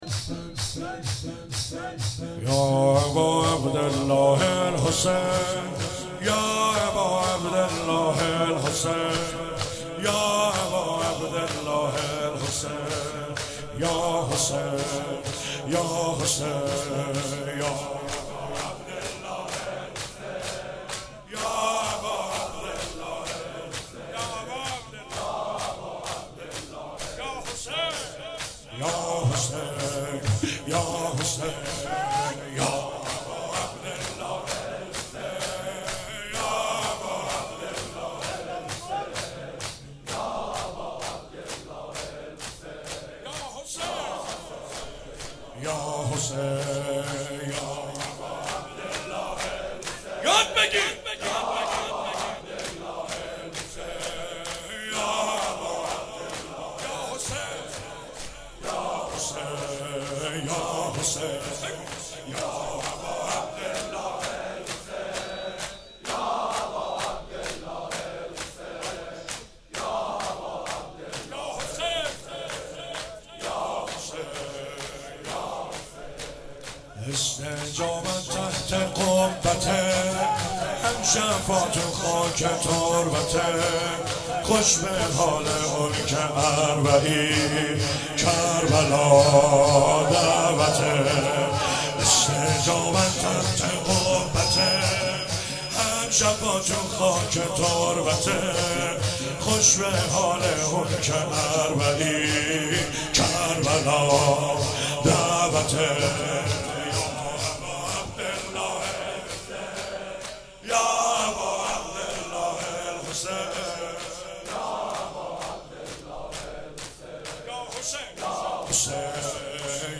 05 heiat alamdar mashhad.mp3